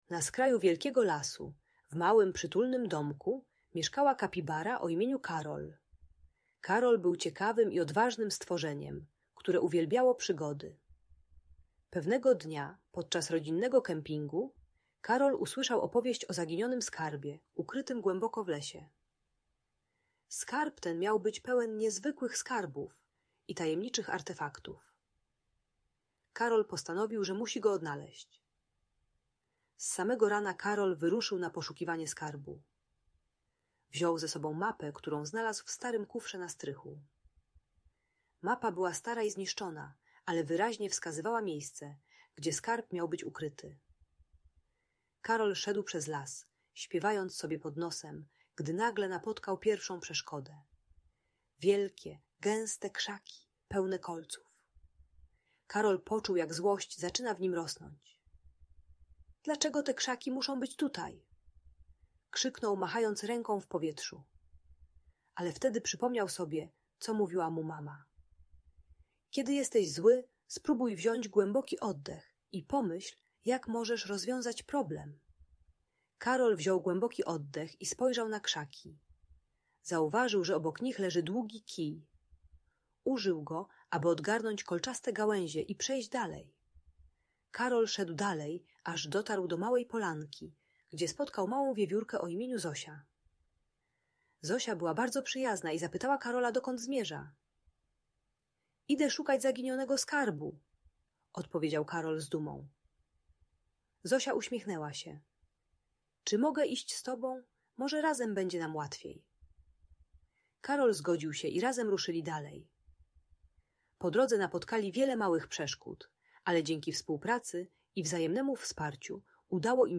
Przygody Karola i Zosi: Opowieść o Zaginionym Skarbie - Audiobajka dla dzieci